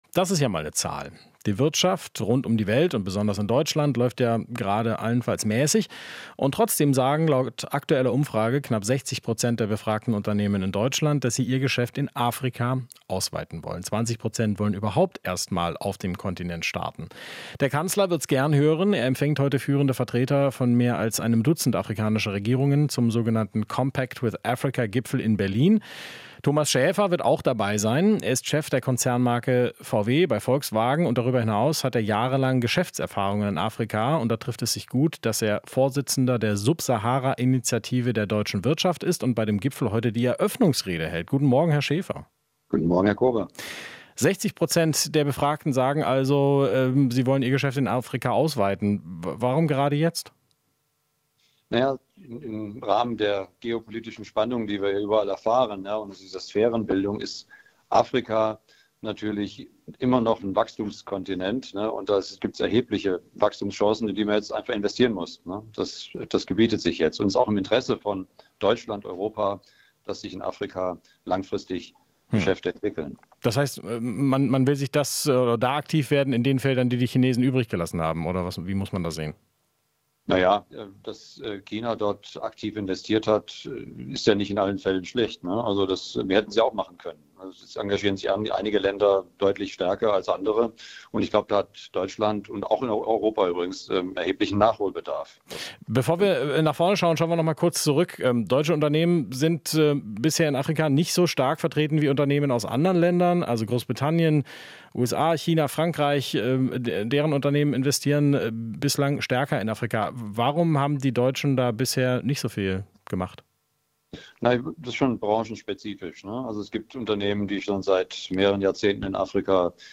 Interview - Subsahara-Afrika Initiative: Eine "EU in Afrika" bietet große Handelschancen